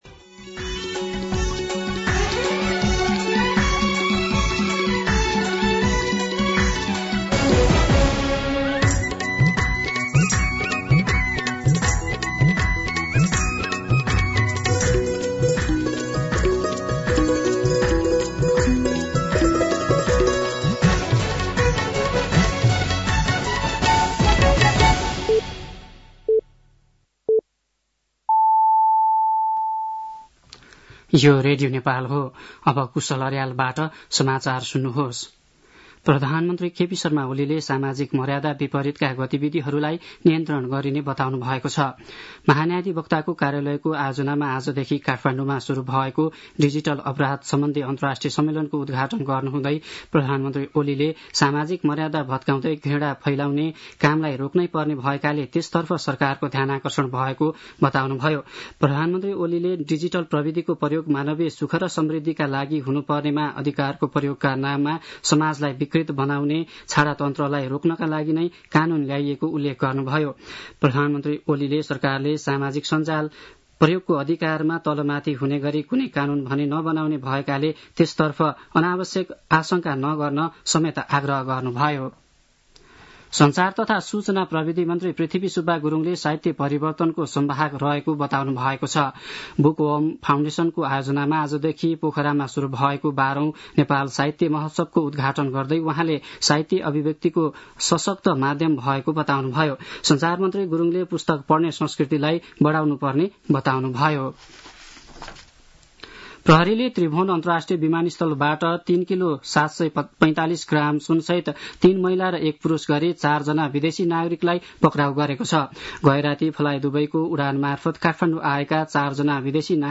दिउँसो ४ बजेको नेपाली समाचार : १६ फागुन , २०८१